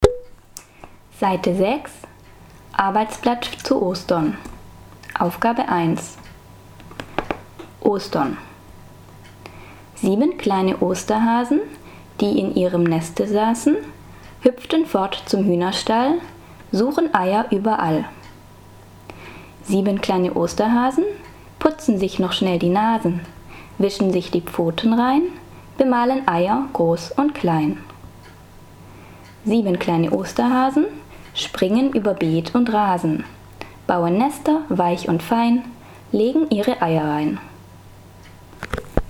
AUDIO-nahrávku básničky "Velikonoce" - cvičení 1 na str. 6-7.
(Němka)